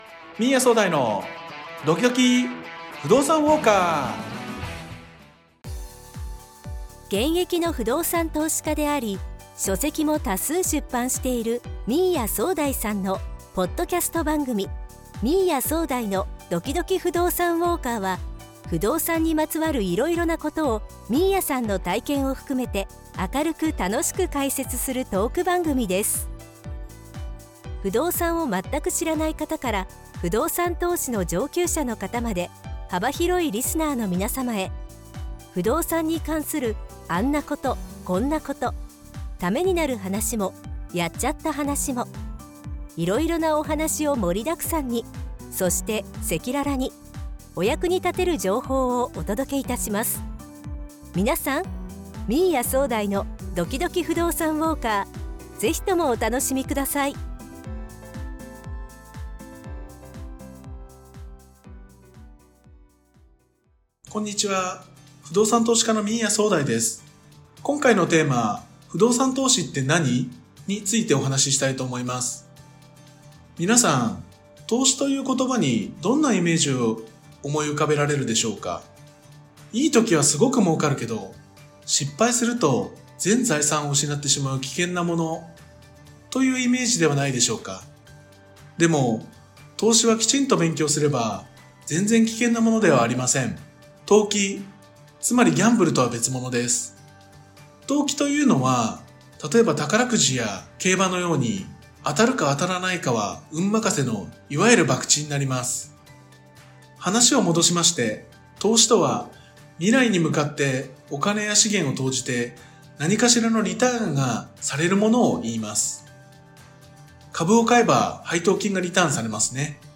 明るく楽しく解説するトーク番組です。